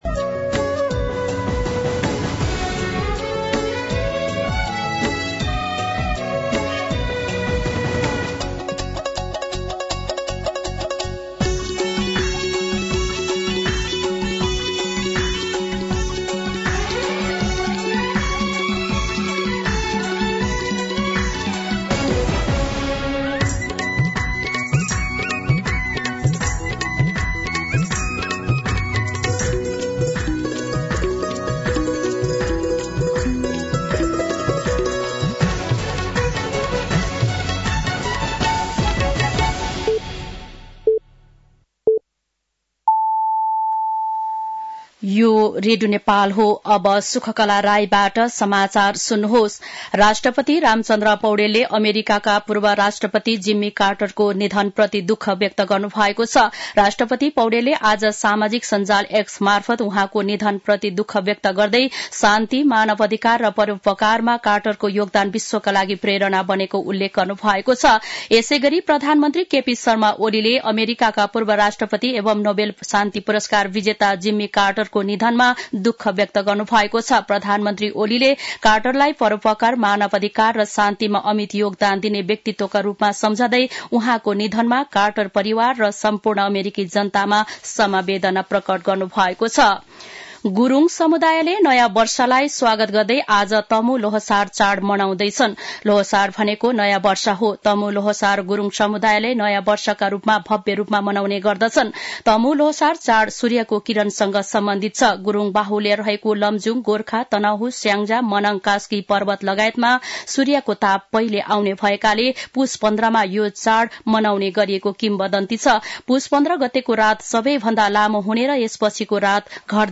दिउँसो ४ बजेको नेपाली समाचार : १६ पुष , २०८१
4-PM-News-9-15.mp3